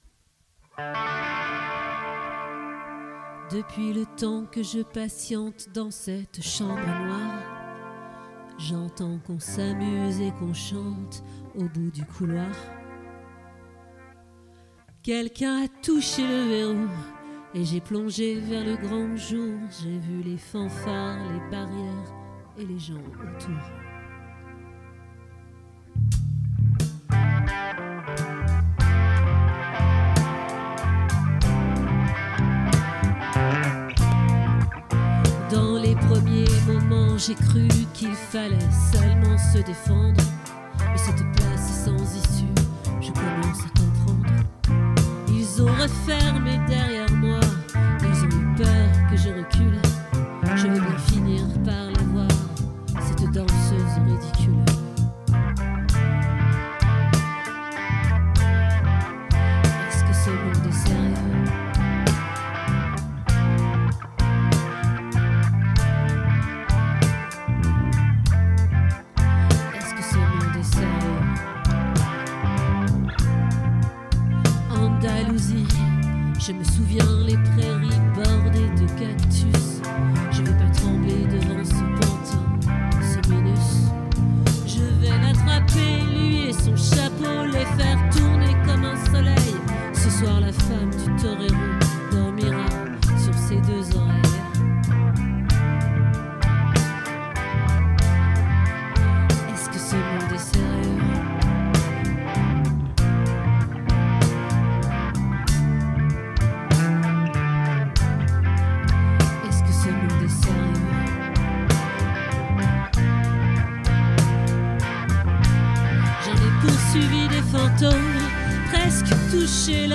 🏠 Accueil Repetitions Records_2025_12_08